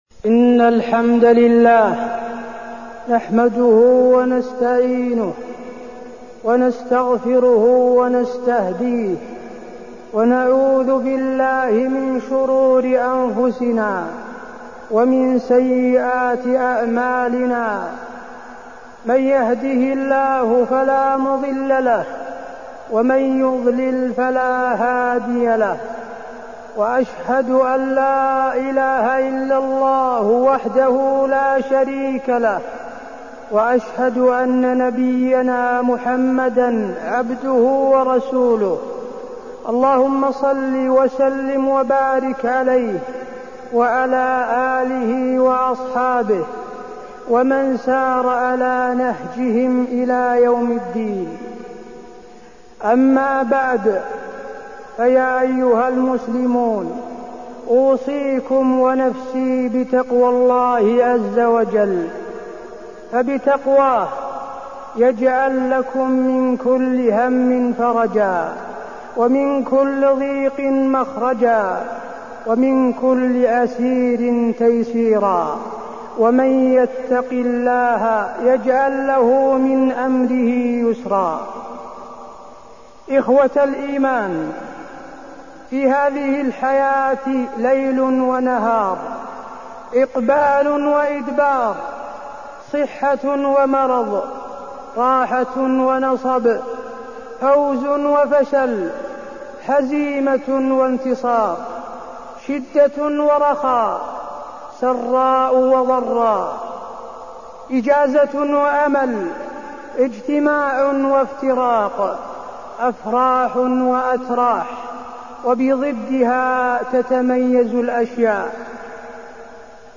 خطبة المؤمن الصادق والإبتلاء وفيها: طبيعة الحياة الدنيا، وحال المؤمن الصادق مع الحياة الدنيا، الدنيا ليست طريقا مفروشا بالورود
تاريخ النشر ٢٠ جمادى الأولى ١٤١٩ المكان: المسجد النبوي الشيخ: فضيلة الشيخ د. حسين بن عبدالعزيز آل الشيخ فضيلة الشيخ د. حسين بن عبدالعزيز آل الشيخ المؤمن الصادق والإبتلاء